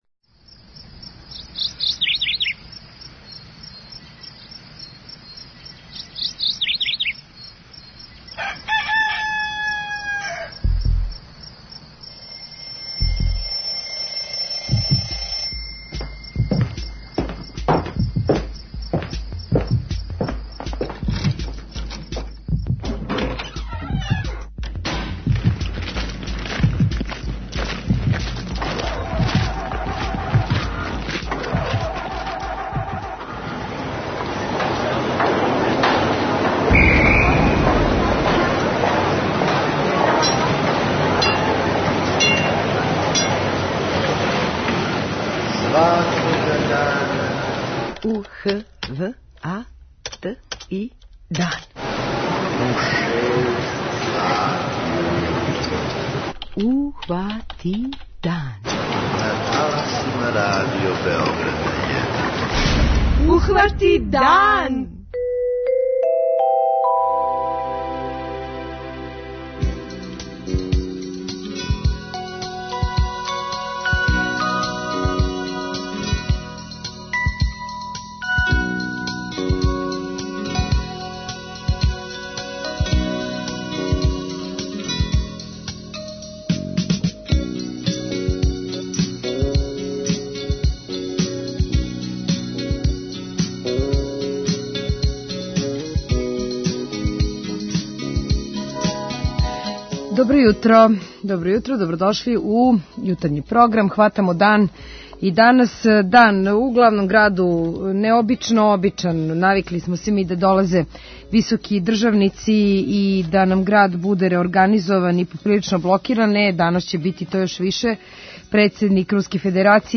У јутарњем програму имаћете прилику да чујете нашег репортера са места која би требало председник Путин да посети, укључићемо у програм и дежурне из градских служби.